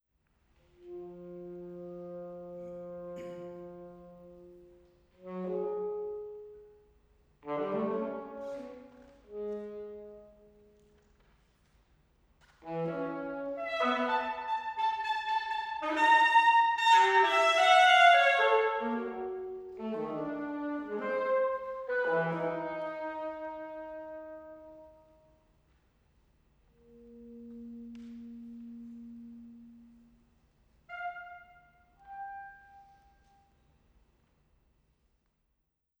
alto saxophone
Norwegian Academy of Music, Lindemansalen, June 2, 2013